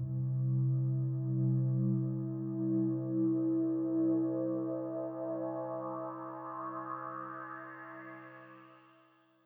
The play utilizes many trumpeting cues to signal arrivals of nobility and of the armies during the battle scenes: these could fit within the aural framework through a change in instrumentation: electric guitars and synthesizers have a similar timbre to trumpets (many synthesizers have presets specifically mimicing brass), and could bring the necessary energetic fanfare without losing the feel of 60s progressive rock.